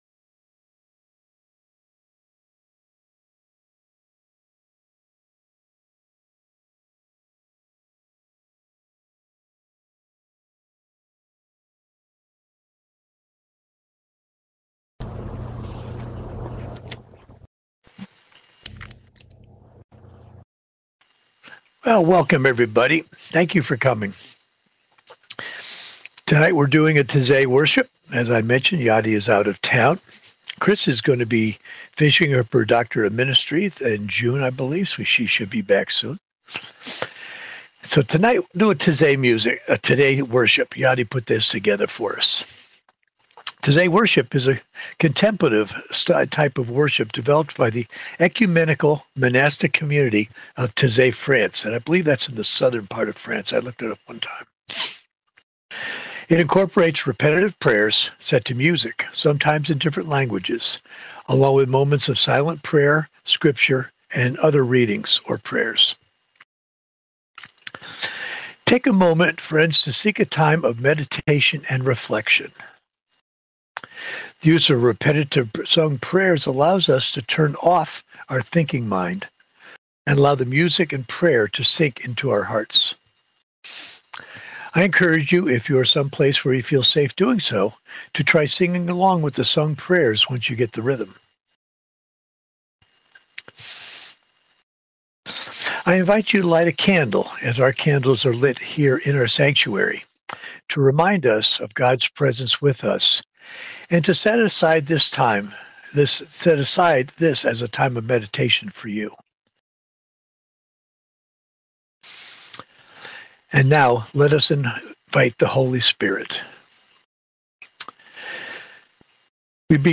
2026 Second Sunday in Easter